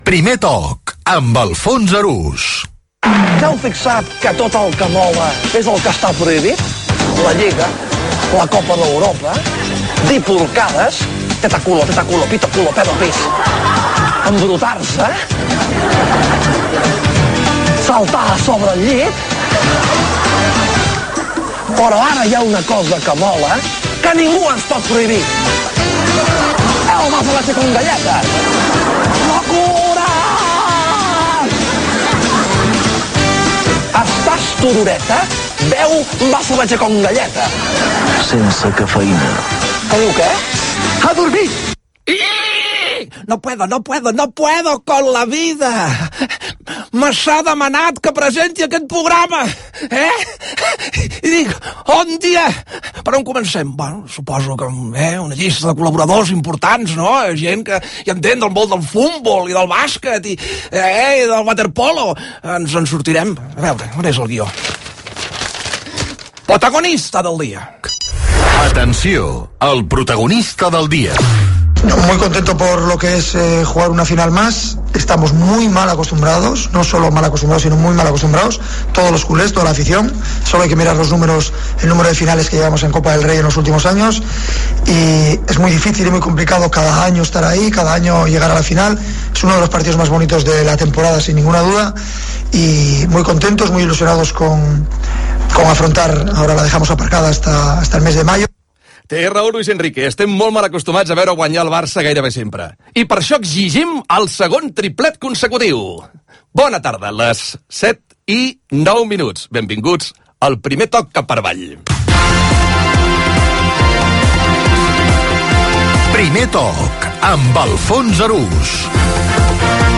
Enregistrament de fa anys.
Esportiu